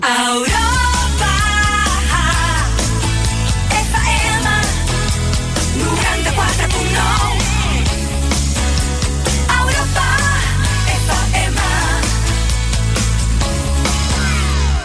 7690e894aa54cfce82f87b0d23fb27ec2f0a89b3.mp3 Títol Europa FM Barcelona Emissora Europa FM Barcelona Cadena Europa FM Titularitat Privada estatal Descripció Indicatiu de l'emissora a Barcelona: 94.9 FM.